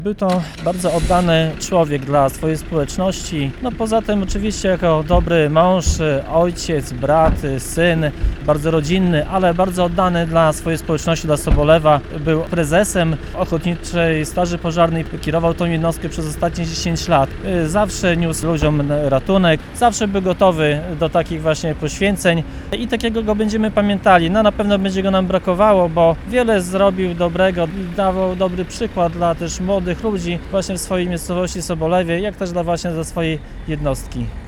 Msza pogrzebowa odbyła się w kościele pod wezwaniem św. Piotra i Pawła w Suwałkach.
– Zawsze był gotów pomagać innym i stanowił wzór dla młodych ludzi – mówił o zmarłym Zbigniew Mackiewicz, wójt gminy Suwałki, wiceprezes Zarządu Oddziału Powiatowego Związku OSP RP.